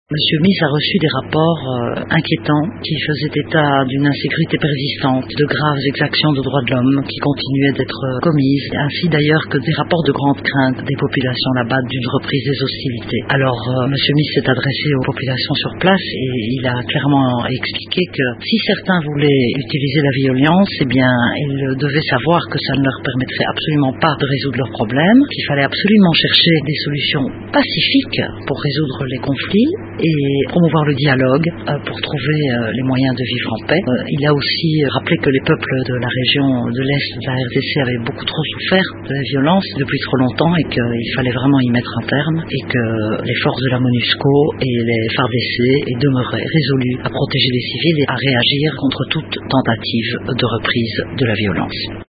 au cours de la conférence de presse hebdomadaire de la mission onusienne à Kinshasa